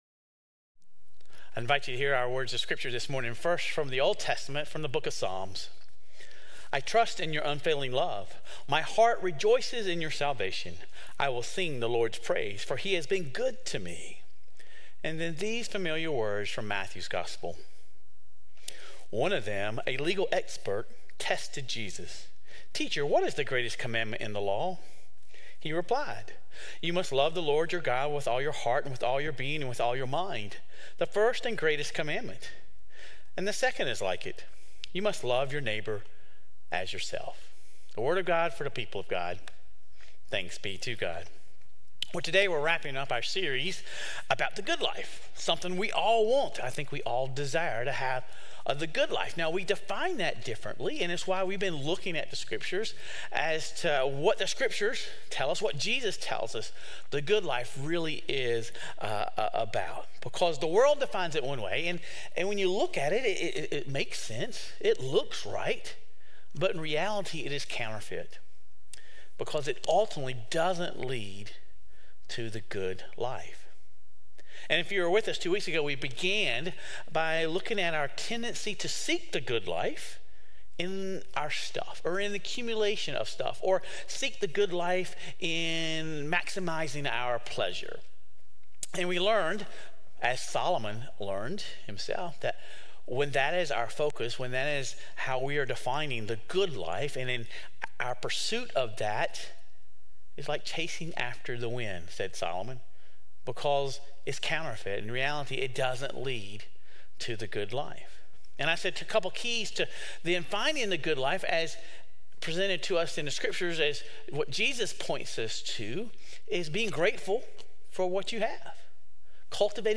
Sermon Reflections: How did this sermon challenge traditional notions of success and "the good life"?